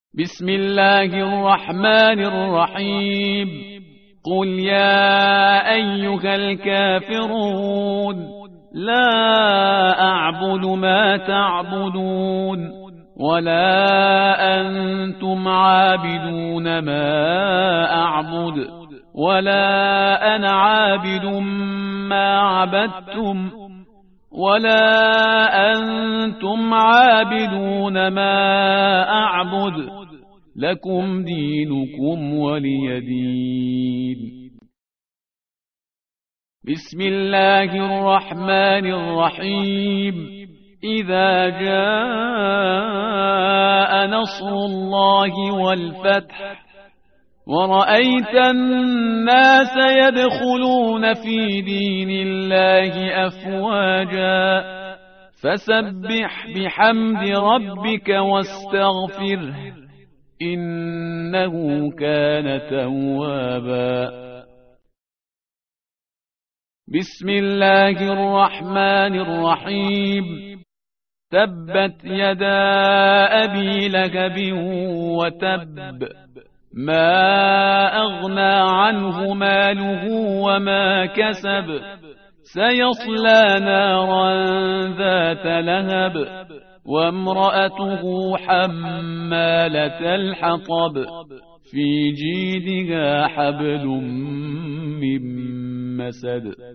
tartil_parhizgar_page_603.mp3